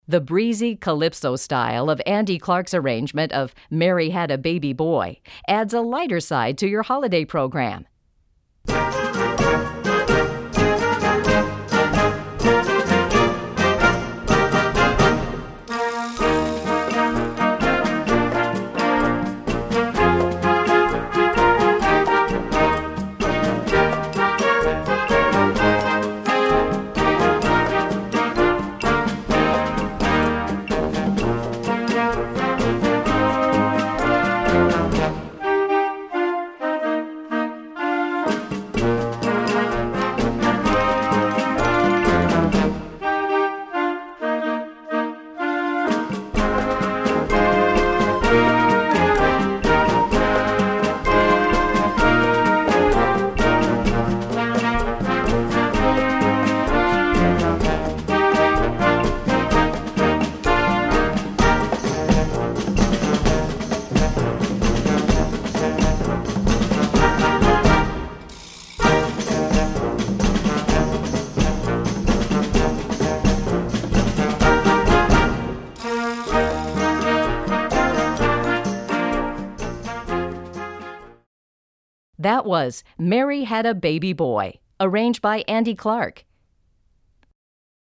Besetzung: Blasorchester
calypso rhythms